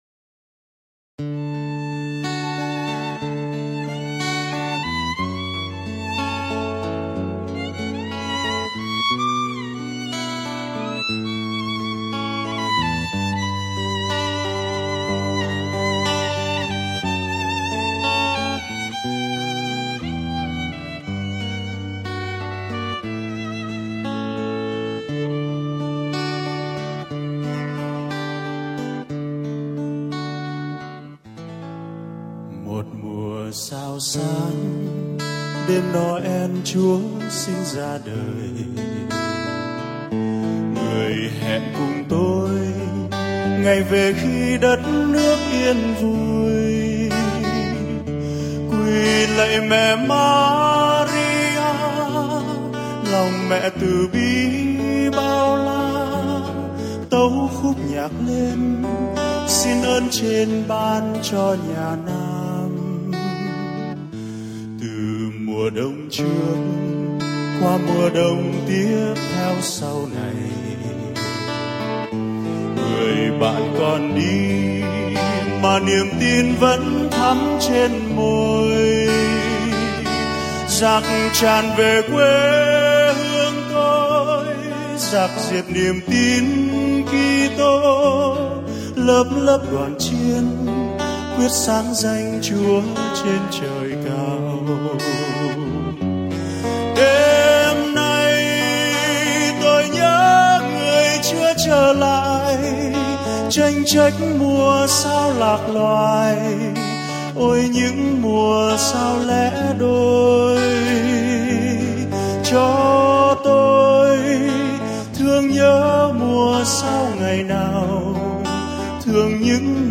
Nhạc Giáng Sinh